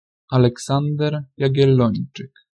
Aleksander Jagiellon (polska: Aleksander Jagiellończyk [a.lɛkˈsan.dɛr jaɡi̯ɛlˈlɔɲt͡ʃɨk] (